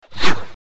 [Track 12] Whoosh Sound Effect.wav